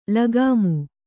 ♪ lagāmu